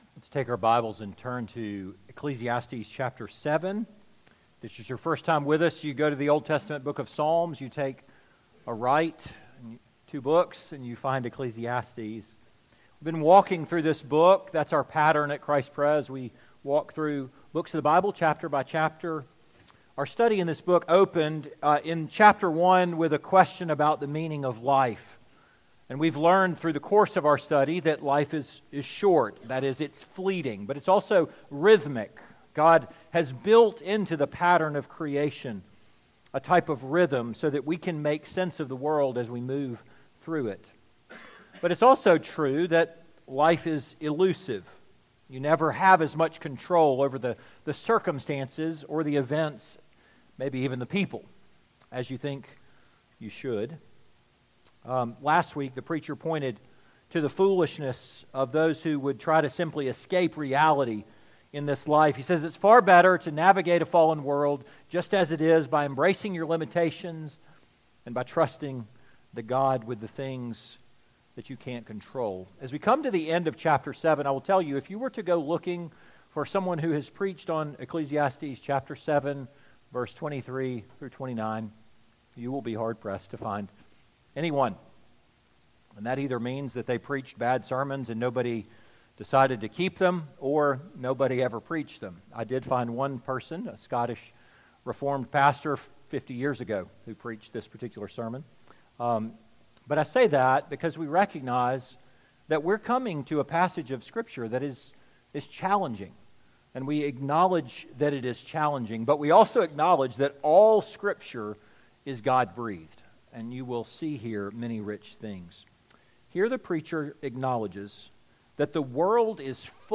2025 The Heart of the Problem Preacher